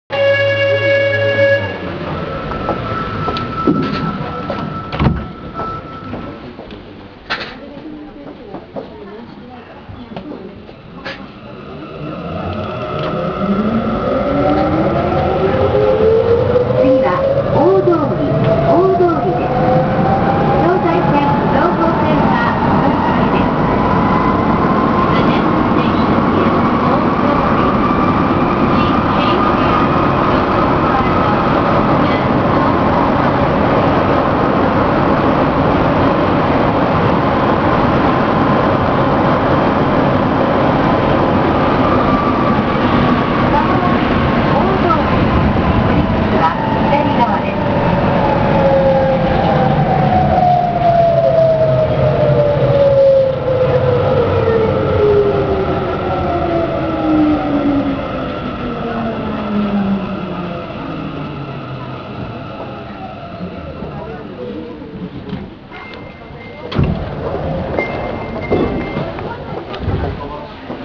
・5000形（日立2レベルIGBT）走行音
【南北線】さっぽろ〜大通（1分10秒：386KB）
いわゆる「新5000形」に区分される5118F以降の編成と、前述の日立3レベルIGBTを採用していた車両の現在の走行音がこちらになります。あまり日立らしくない音で、やや派手なイメージ。